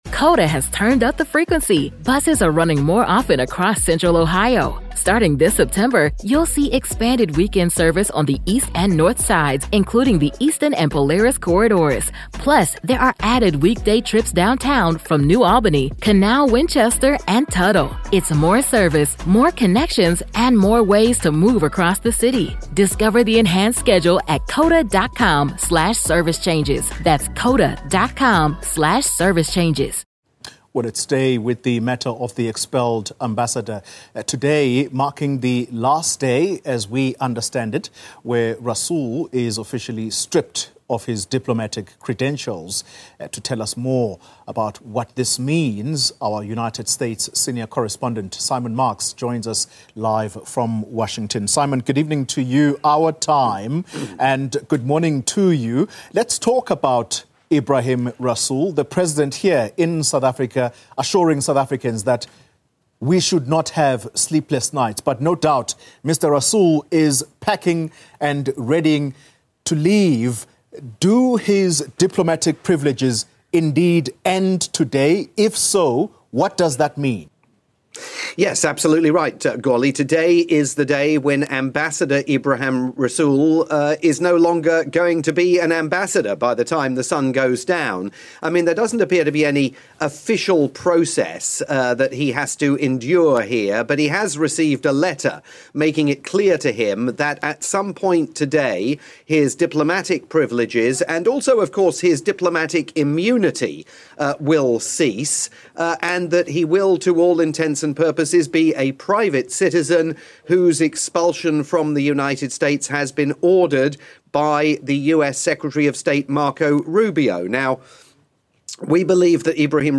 live update on the situation engulfing South African Ambassador Ebrahim Rasool, for Newzroom Afrika TV